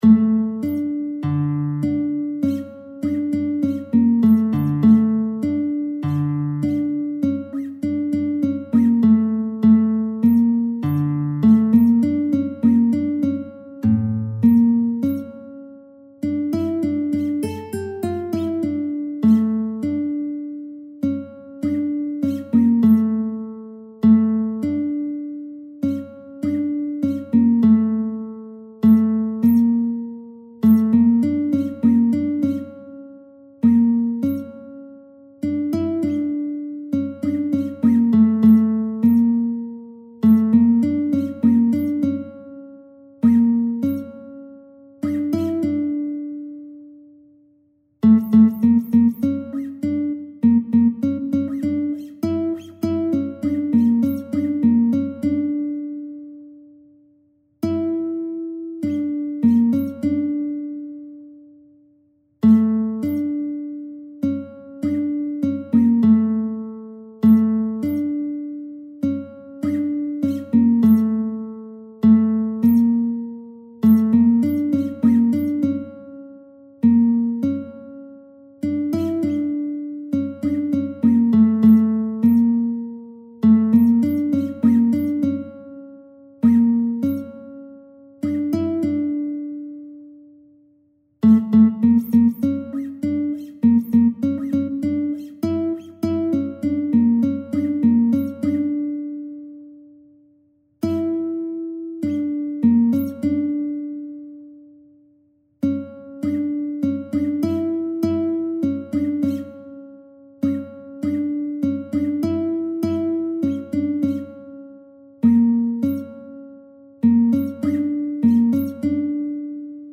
آهنگ:سه گاه